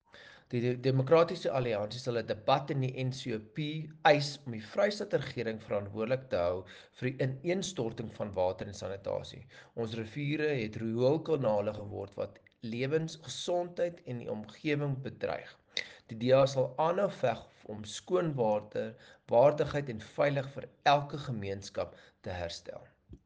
Afrikaans soundbites by Dr Igor Scheurkogel MP and